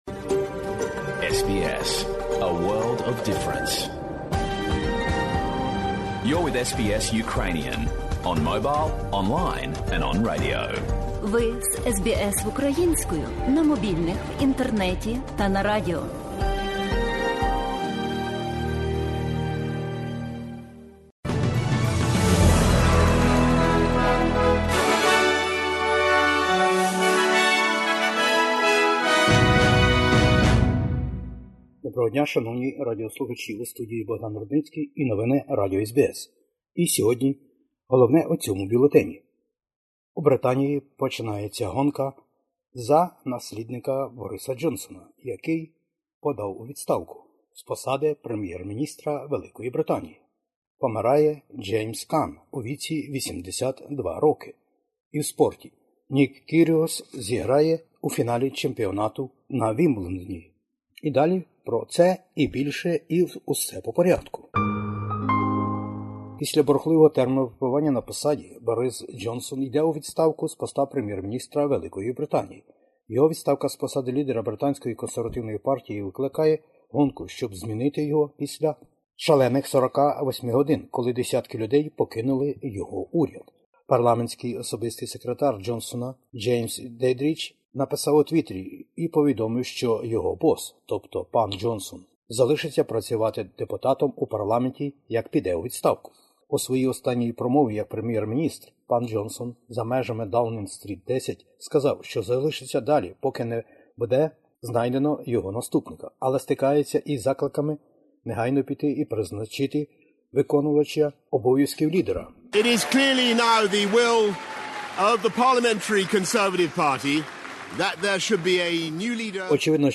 Бюлетень SBS новин українською мовою. Відставка Бориса Джонсона.